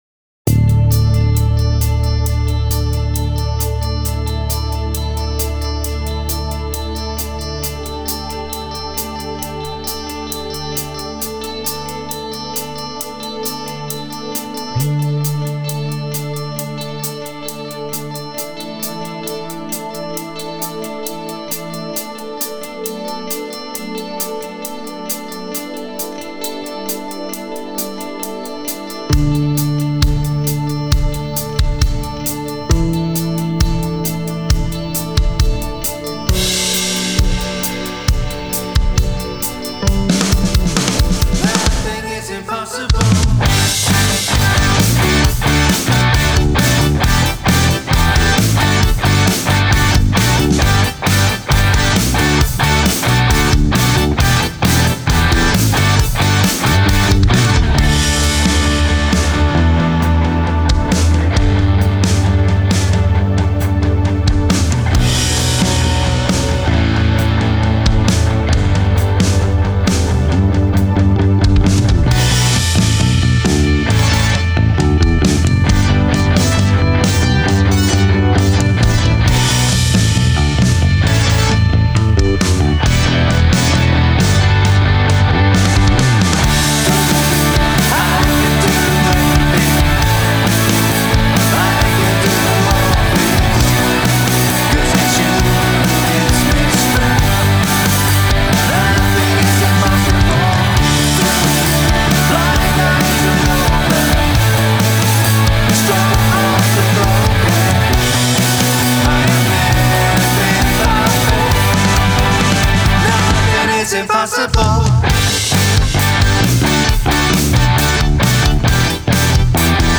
Key: D BPM: 134 Time sig: 4/4 Duration:  Size: 11.5MB
Contemporary Rock Worship